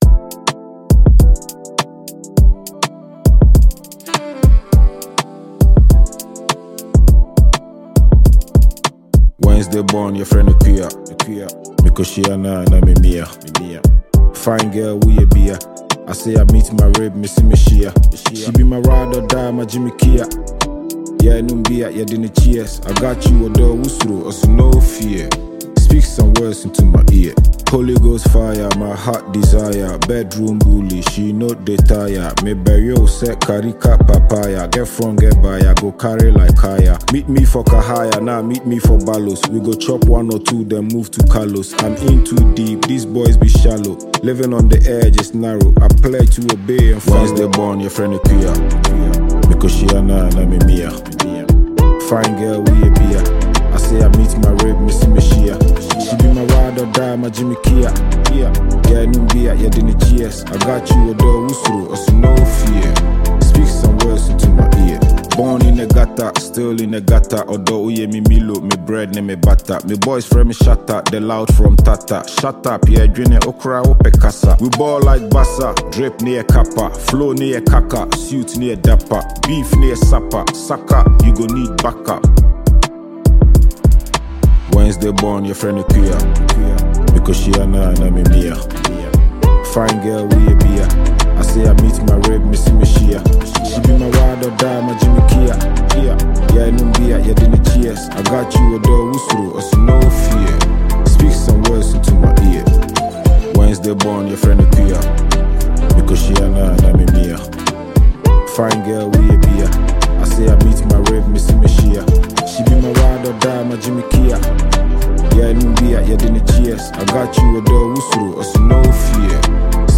Genre: Afro-Rap / Storytelling